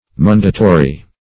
Search Result for " mundatory" : The Collaborative International Dictionary of English v.0.48: Mundatory \Mun"da*to*ry\, a. [L. mundatorius.] Cleansing; having power to cleanse.